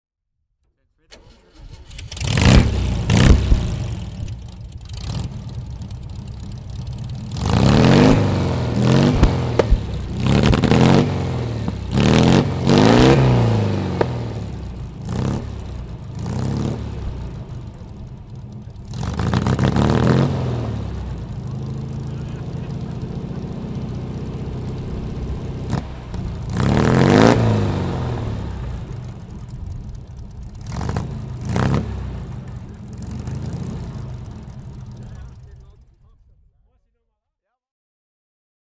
And, of course, the sound of the engine is not missing.
Formcar Formel V (1965) - Starten und Leerlauf